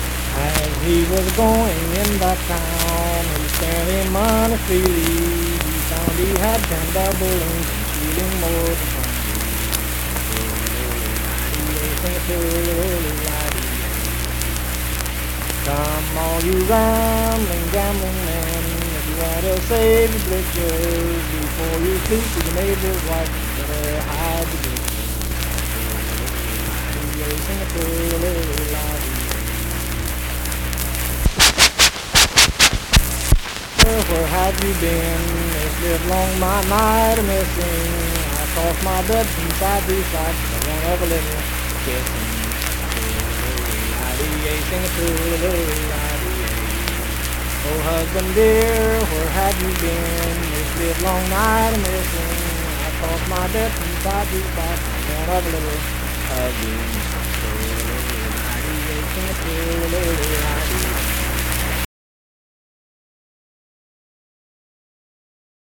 Unaccompanied vocal music
Voice (sung)
Pleasants County (W. Va.), Saint Marys (W. Va.)